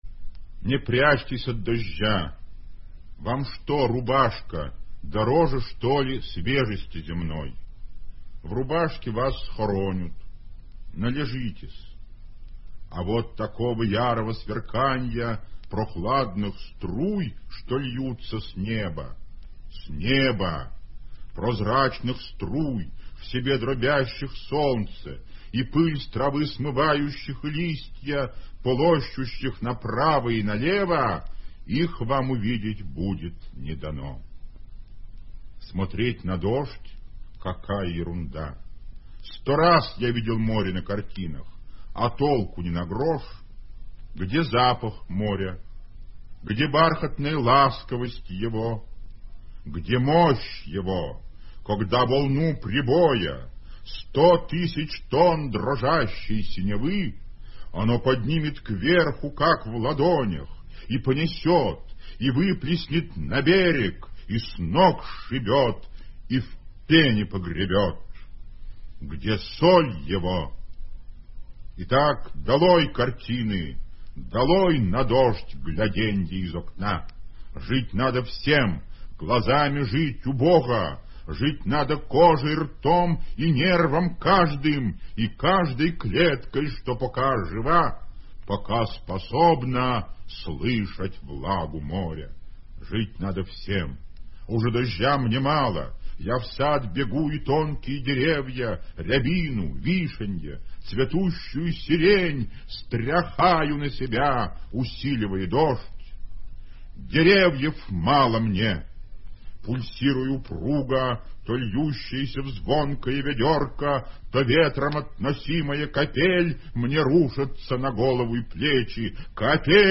1. «Владимир Солоухин – Не прячьтесь от дождя (интересно читает автор)» /